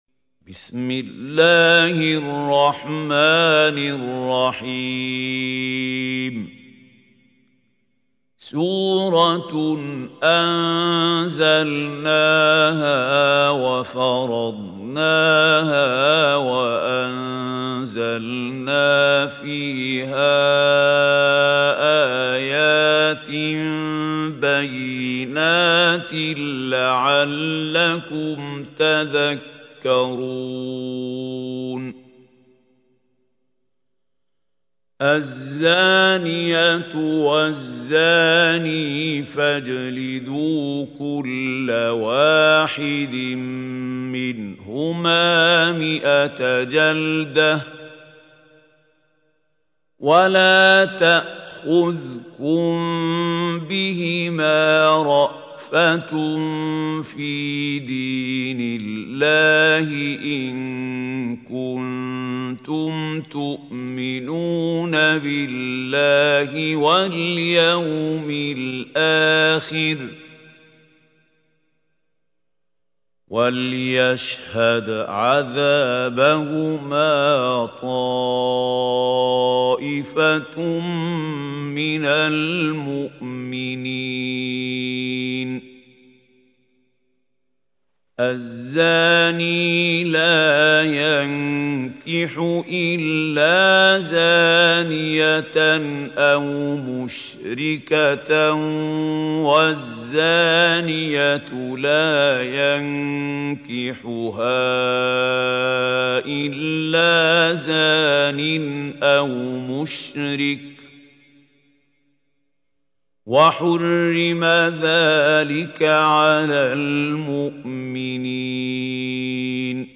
سورة النور | القارئ محمود خليل الحصري